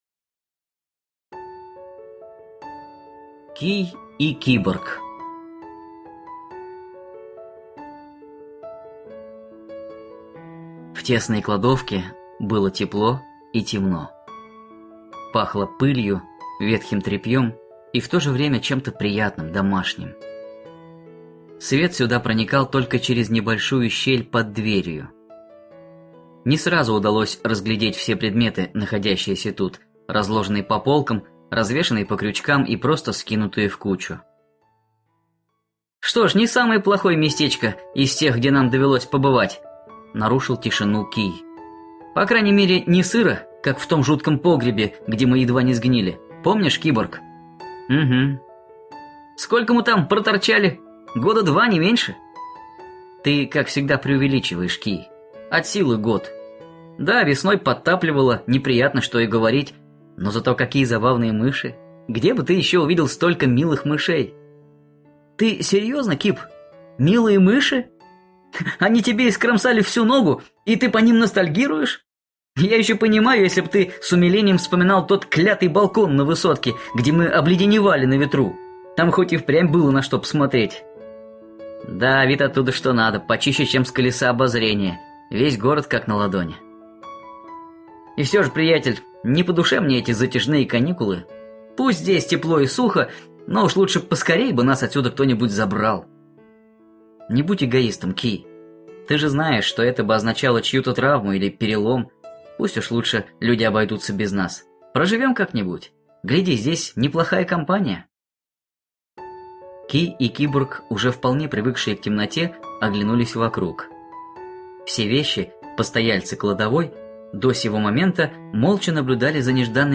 Скачать, слушать онлайн аудиокнигу Кий и Киборг